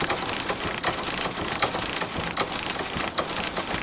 Robot Walking_Broken.wav